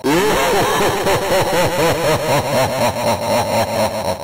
Click here to listen to Satan's amaaaaazing laughter!]
personal-nightmare-satan-laugh.wav